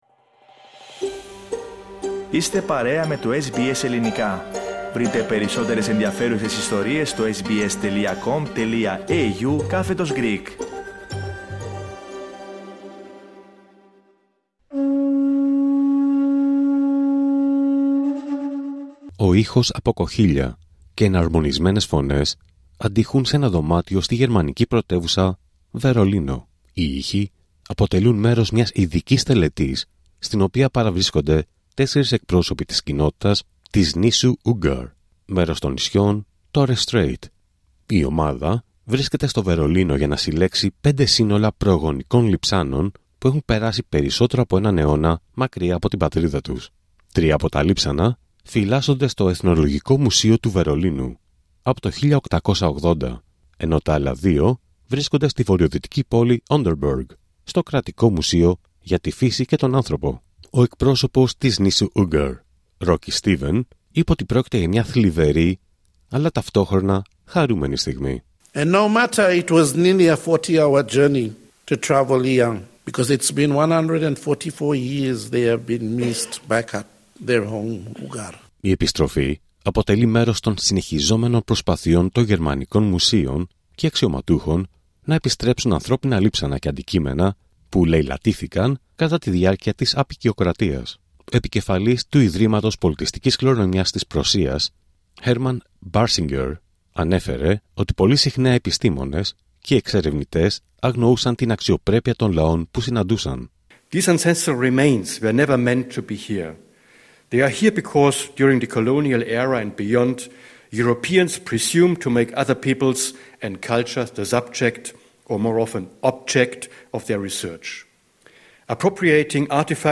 Ο ήχος από κοχύλια και εναρμονισμένες φωνές αντηχούν σε ένα δωμάτιο στη γερμανική πρωτεύουσα, Βερολίνο.